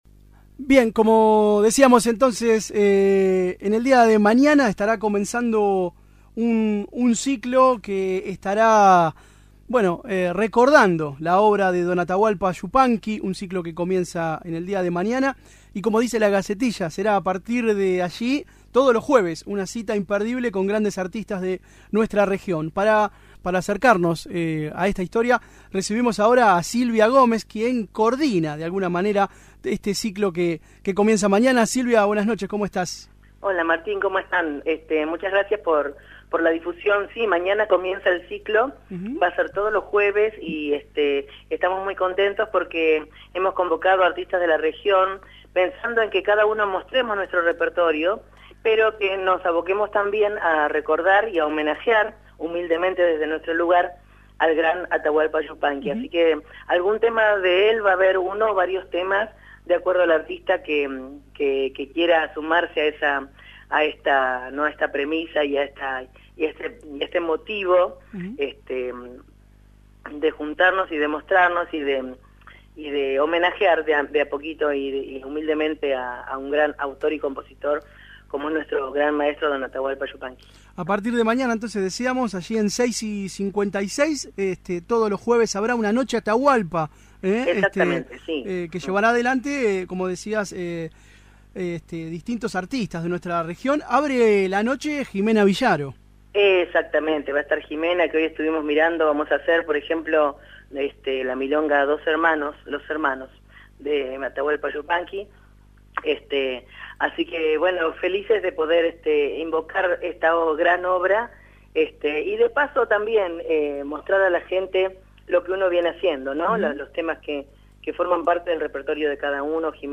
Escuchá la entrevista completa: Cacodelphia